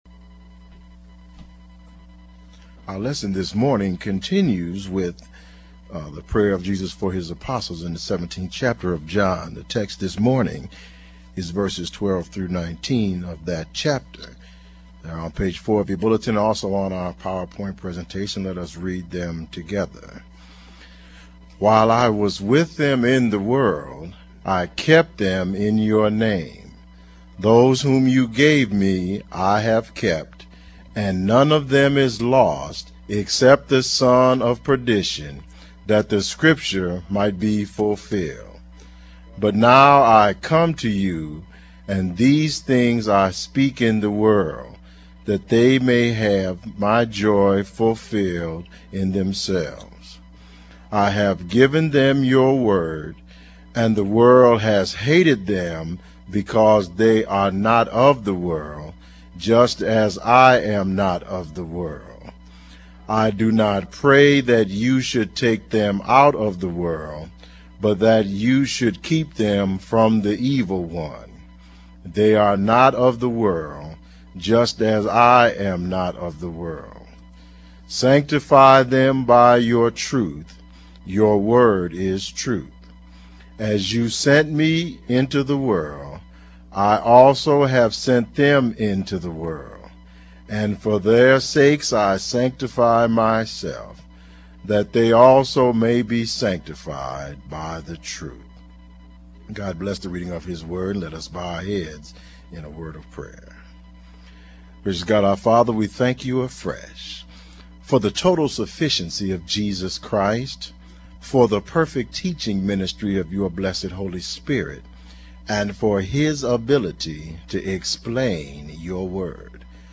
Family Life Baptist Church : Jesus' Prayer for the Apostles